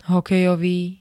Zvukové nahrávky niektorých slov
ruvg-hokejovy.ogg